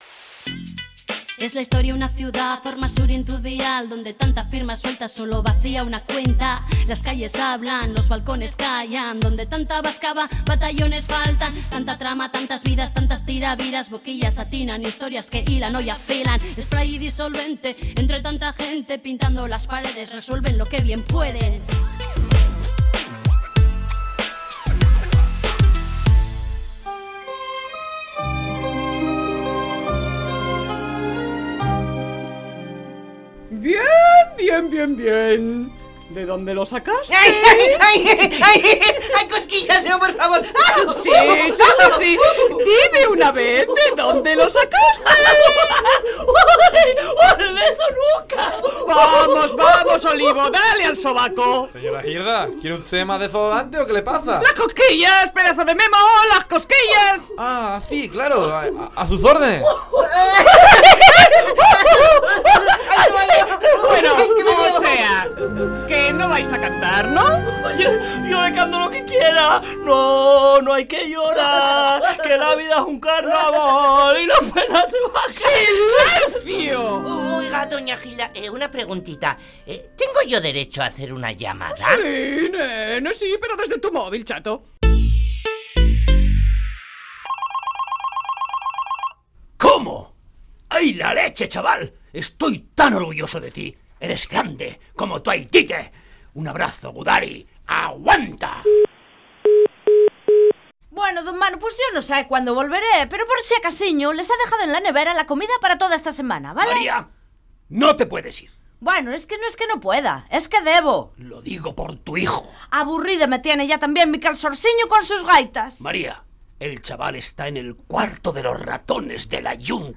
Entrega número 37 de la Radio-Ficción “Spray & Disolvente”